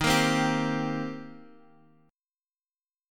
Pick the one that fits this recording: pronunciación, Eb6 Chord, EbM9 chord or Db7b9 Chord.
Eb6 Chord